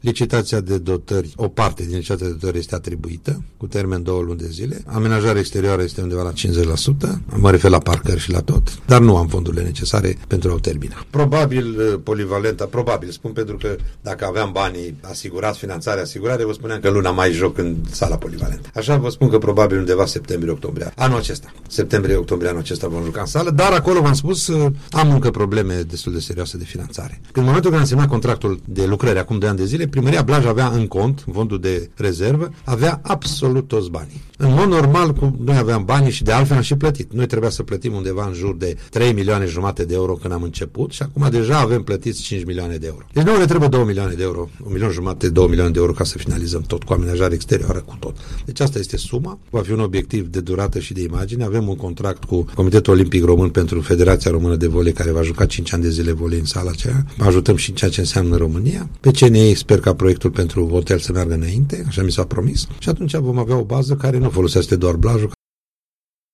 Primarul Gheorghe Valentin Rotar, a declarat la Unirea FM că administrația blăjeană mai are nevoie de circa 2 milioane de euro pentru ca investiția să fie finalizată.